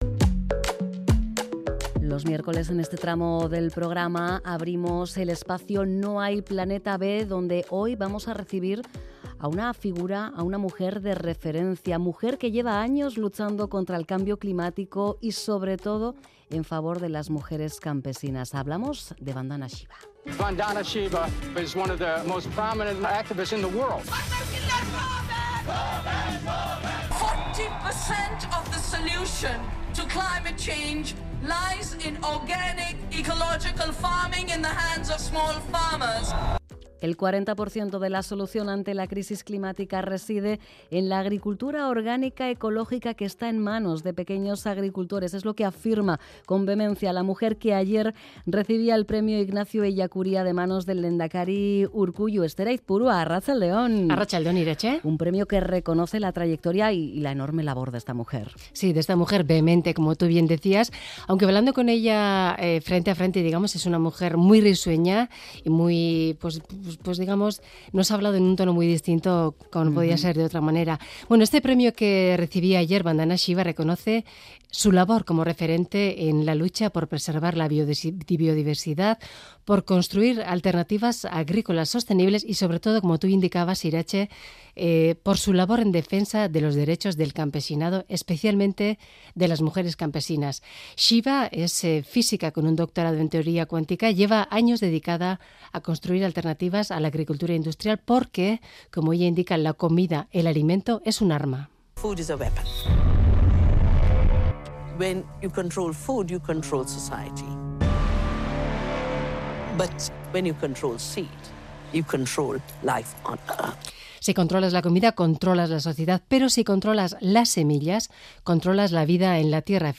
Charlamos con Vandana Shiva, Premio Ignacio Ellacuría 2022, y doctora en Física, que lleva años luchando por los derechos del campesinado, especialmente de las mujeres.